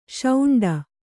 ♪ śauṇḍa